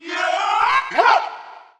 monster2 / fire_ghost / attack_1.wav
attack_1.wav